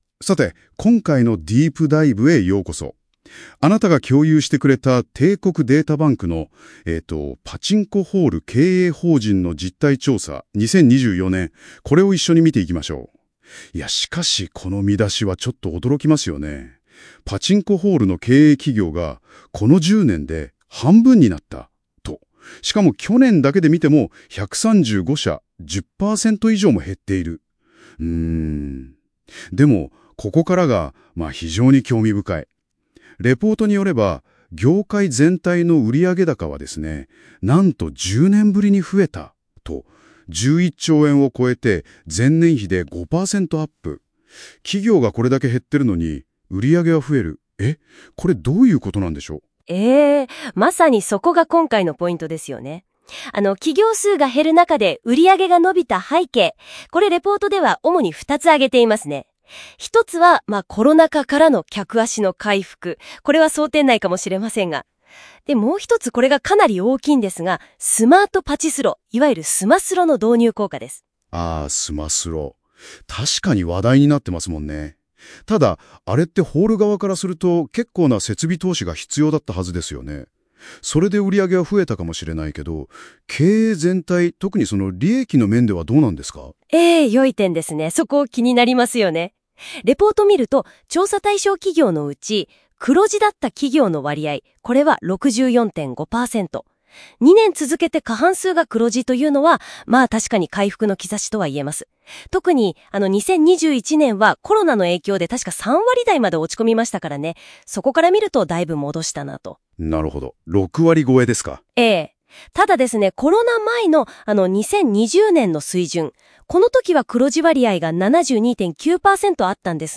※AIで生成／試験運用中